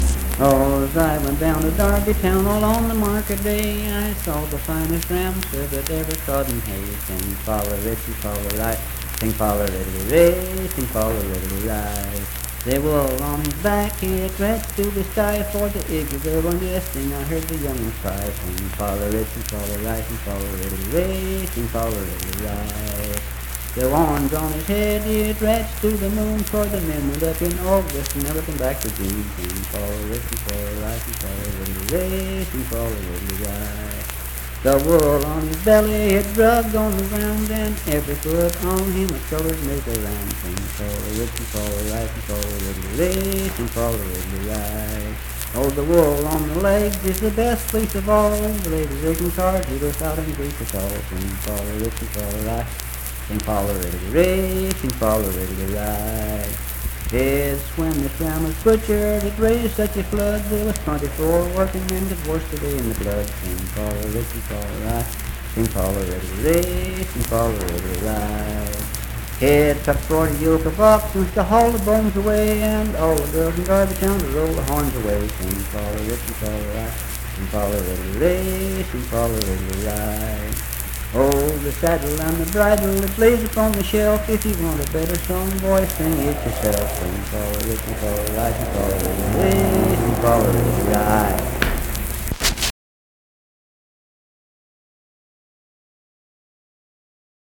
Unaccompanied vocal music performance
Verse-refrain 8d(4w/R).
Voice (sung)